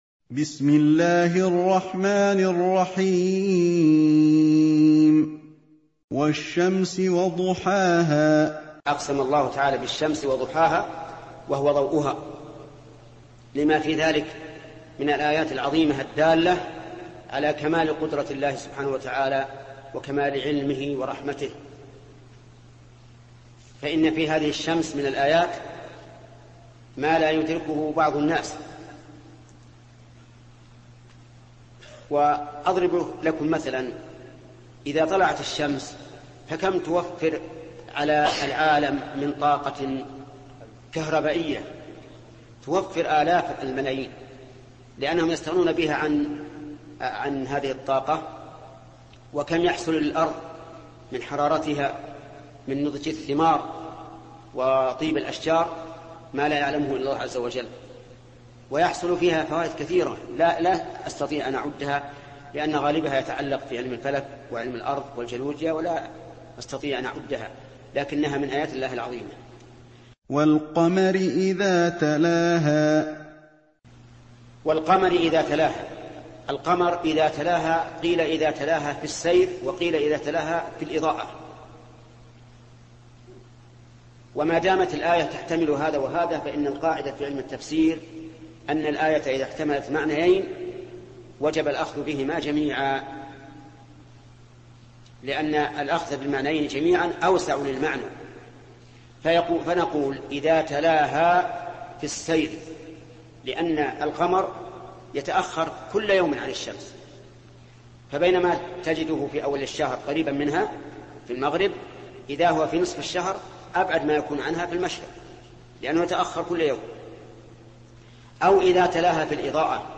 الدرس السادس عشر: من قوله تفسير سورة الشمس، إلى نهاية تفسير سورة الليل.